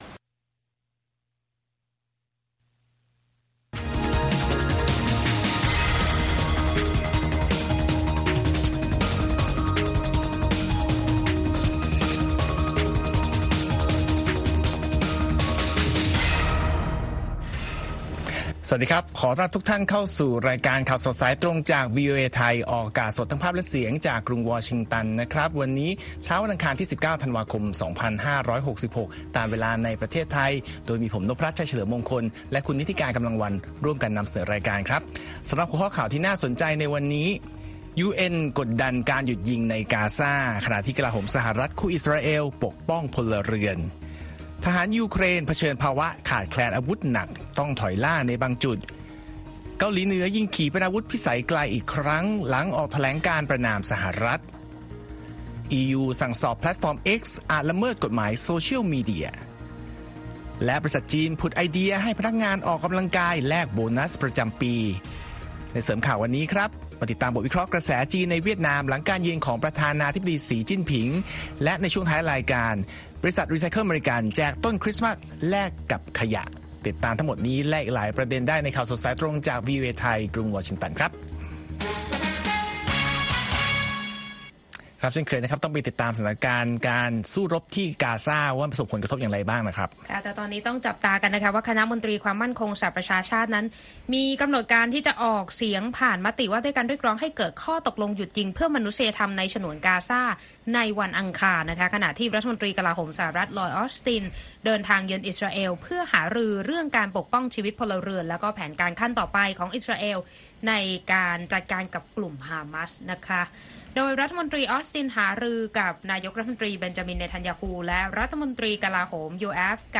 ข่าวสดสายตรงจากวีโอเอไทย 8:30–9:00 น. วันอังคารที่ 19 ธันวาคม 2566